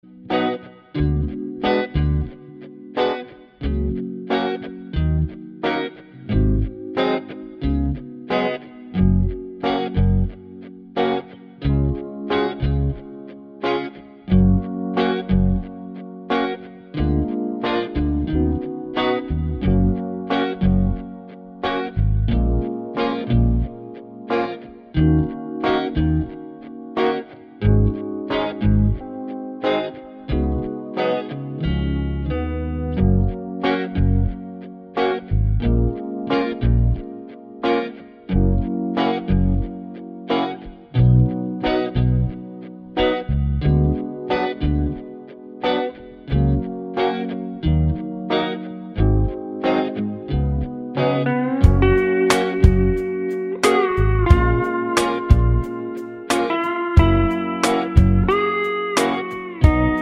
no Backing Vocals Jazz / Swing 3:03 Buy £1.50